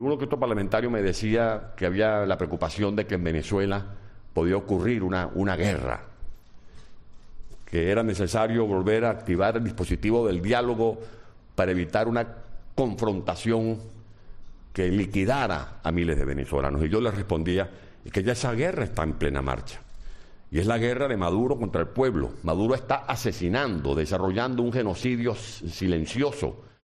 Ledezma, que ha intervenido en la tribuna Fórum Europa de Madrid, rechazó la creación de grupos auspiciados por algunos países para reactivar el diálogo en Venezuela, y consideró que deberían dedicarse a ver cómo Maduro sale del poder.